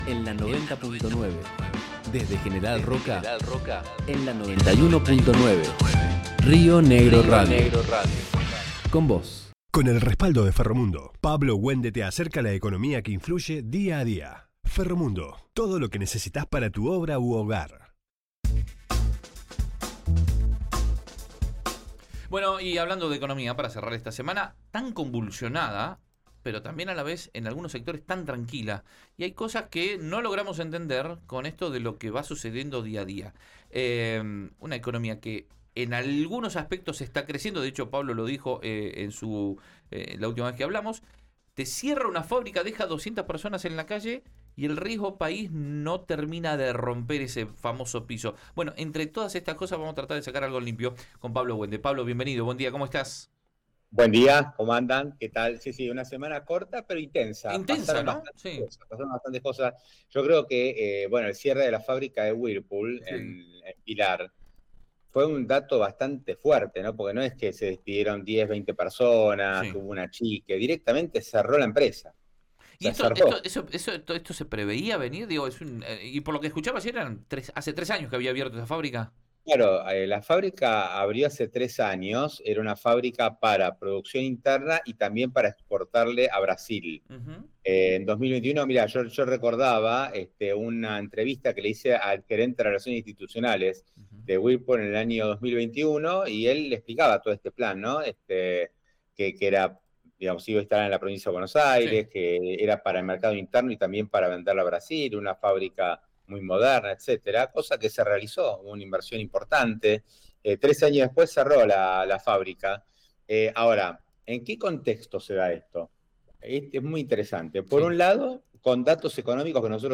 en su columna en Río Negro Radio